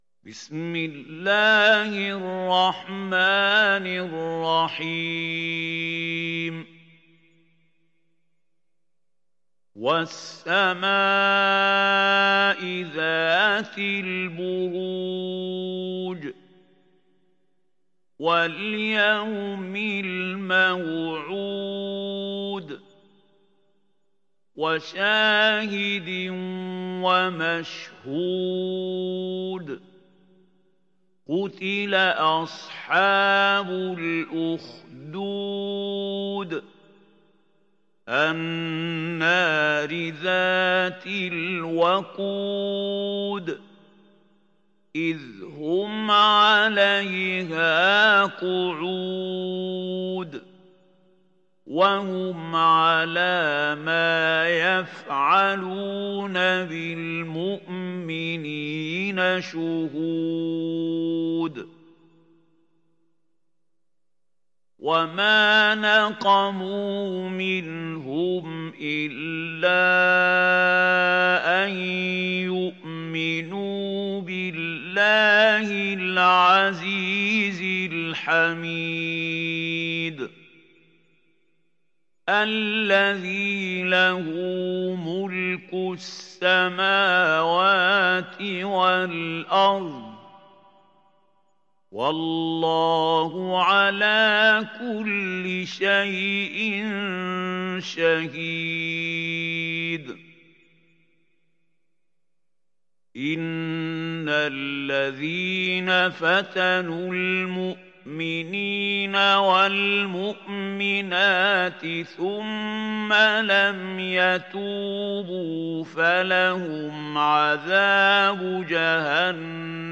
Sourate Al Buruj mp3 Télécharger Mahmoud Khalil Al Hussary (Riwayat Hafs)
Télécharger Sourate Al Buruj Mahmoud Khalil Al Hussary